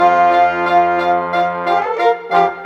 Rock-Pop 07 Brass _ Winds 03.wav